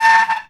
metal_m2.wav